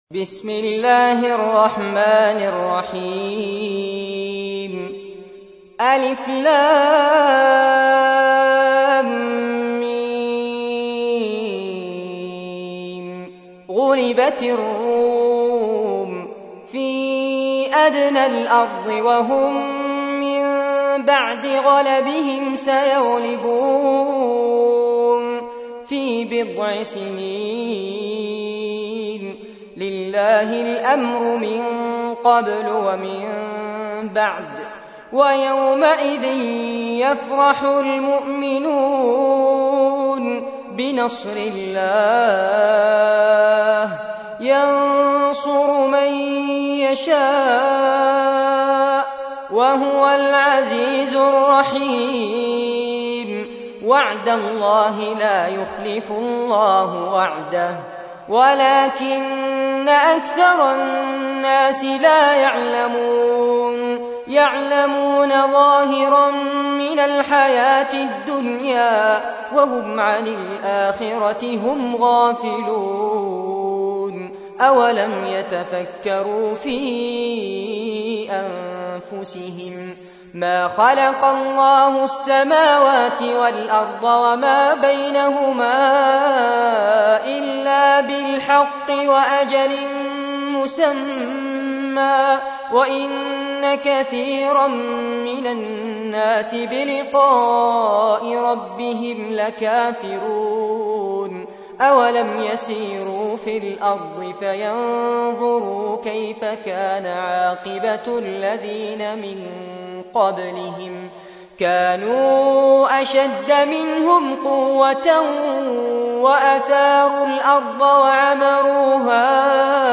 تحميل : 30. سورة الروم / القارئ فارس عباد / القرآن الكريم / موقع يا حسين